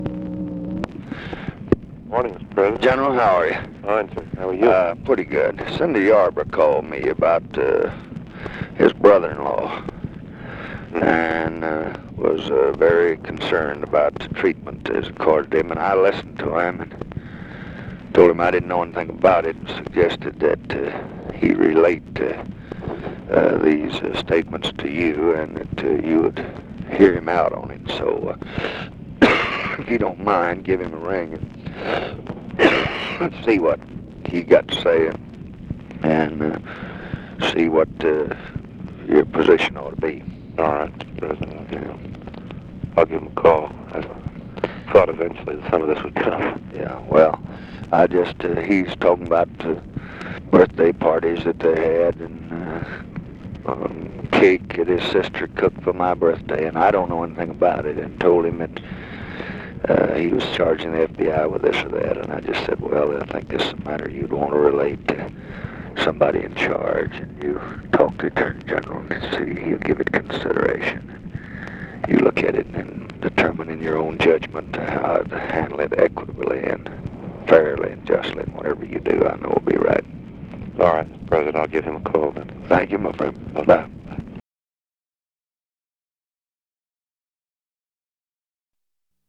Conversation with NICHOLAS KATZENBACH, January 29, 1965
Secret White House Tapes